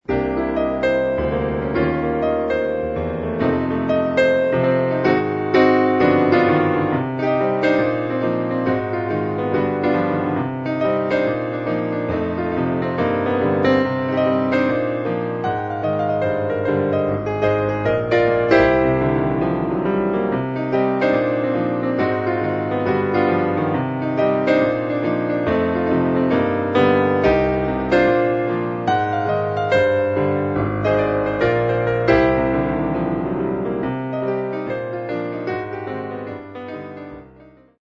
I was feeling down, thinking about some depressing memories, so I played some silly light-hearted stuff to help cheer me up. Gotta love those flatted fifths.